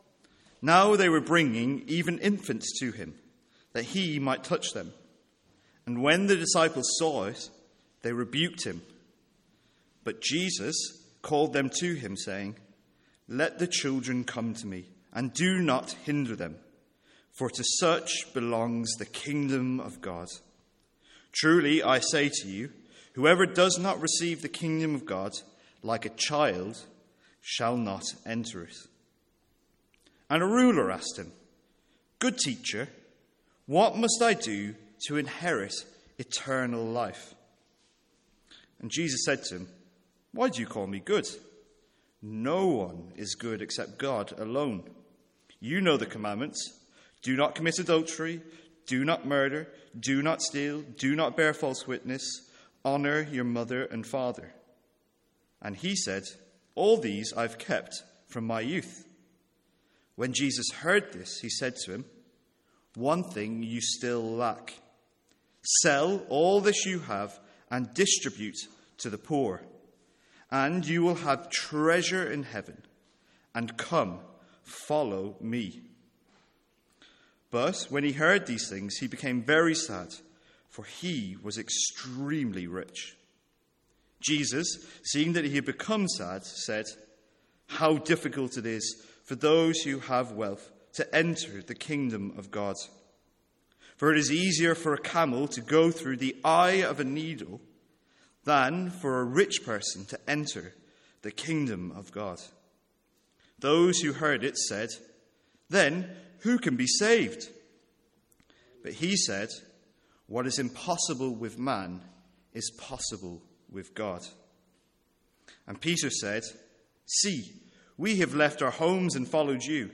Sermons | St Andrews Free Church
From our evening series in Luke's Gospel.